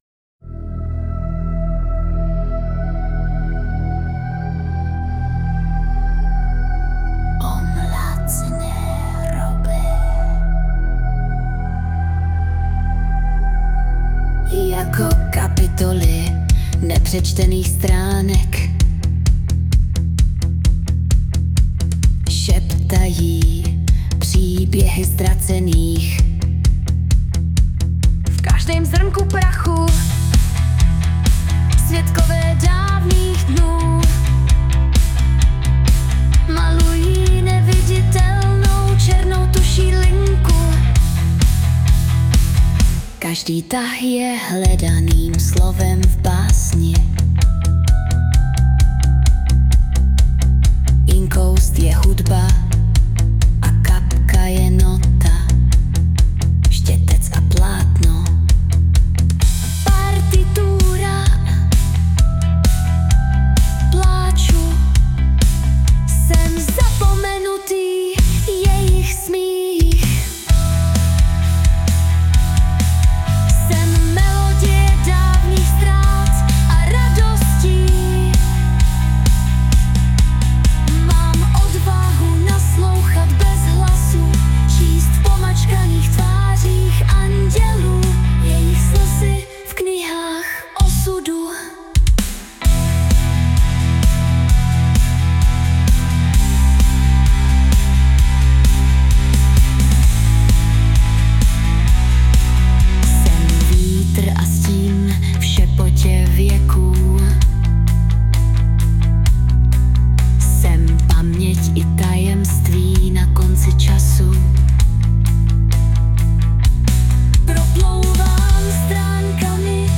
2025 & Hudba, Zpěv a Obrázek: AI